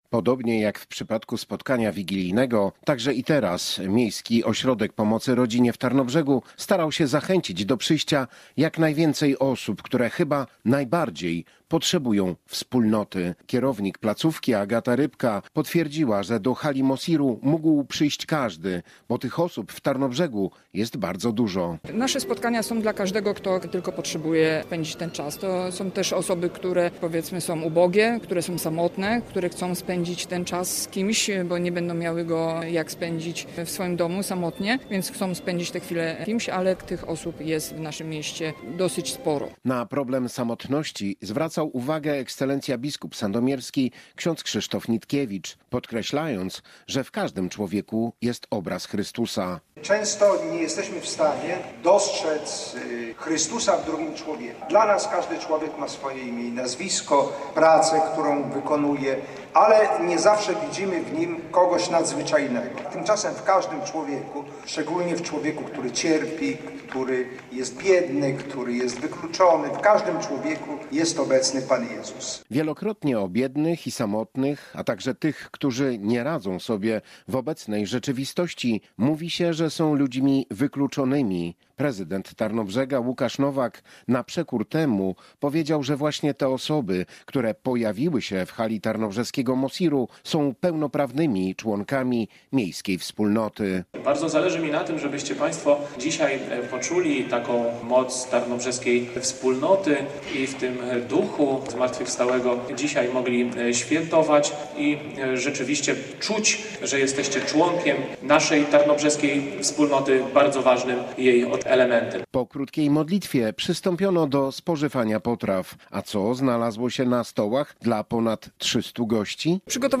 Do hali Miejskiego Ośrodka Sportu i Rekreacji przybyli bezdomni, samotni, niepełnosprawni, seniorzy i potrzebujący pomocy.